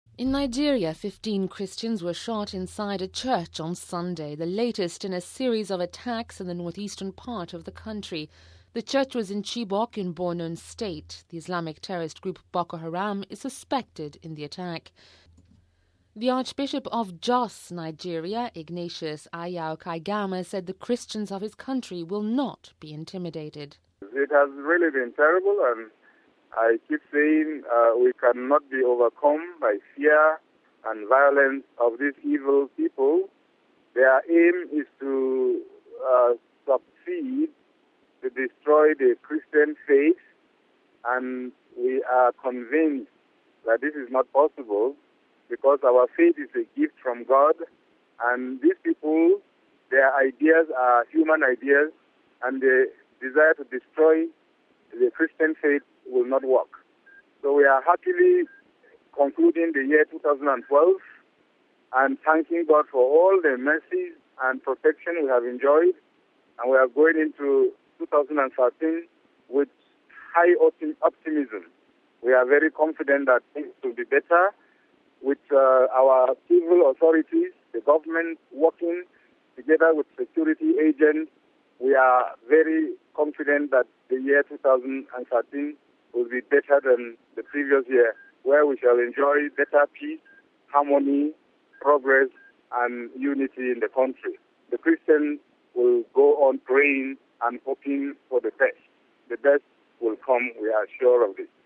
Listen to the comments of Archbishop Kaigama: RealAudio